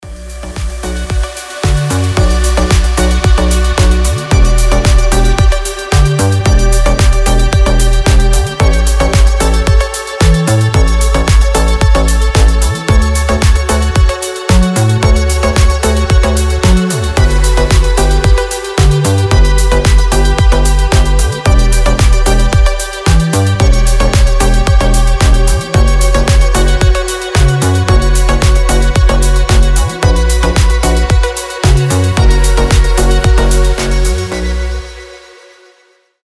• Качество: 320, Stereo
deep house
атмосферные
без слов
красивая мелодия
релакс
расслабляющие
космические
Завораживающая мелодия без слов